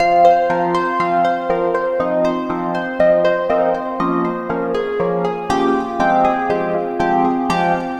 Emotion Lead.wav